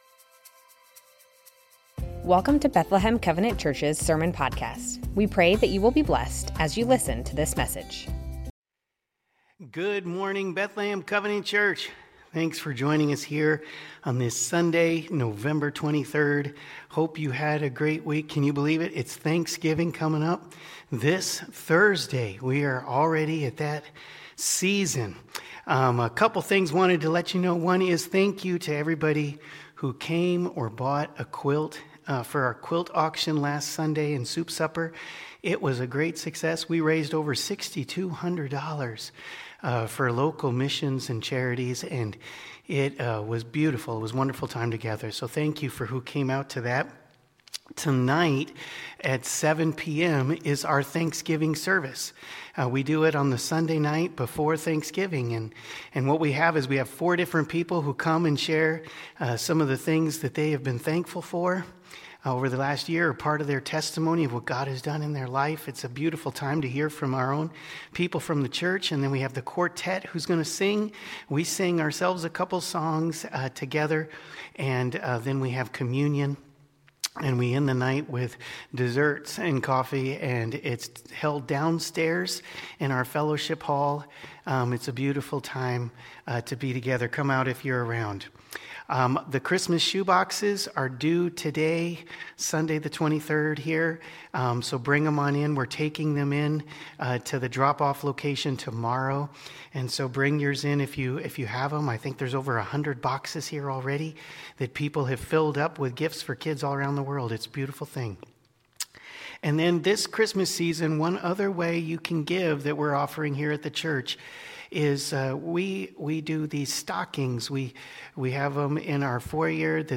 Bethlehem Covenant Church Sermons Rejoice, pray and give thanks Nov 23 2025 | 00:35:12 1x 00:00 / 00:35:12 Subscribe Share Spotify RSS Feed Share Link Embed